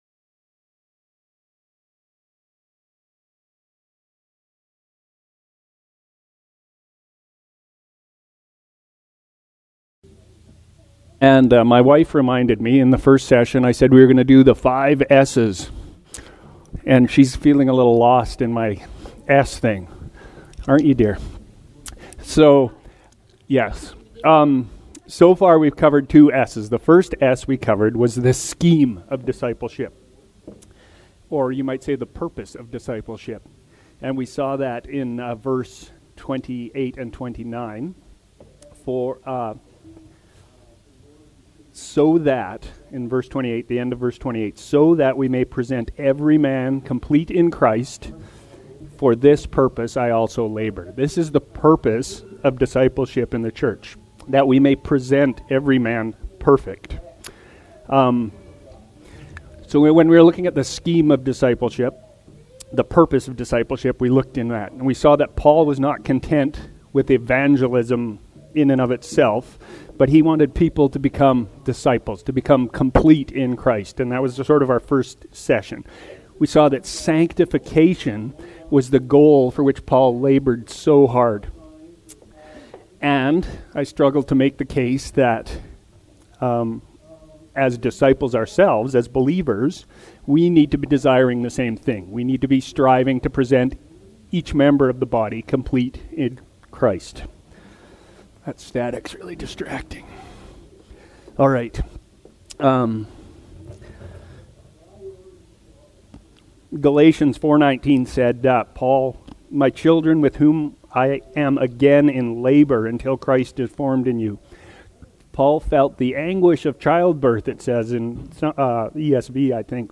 Category: Sunday School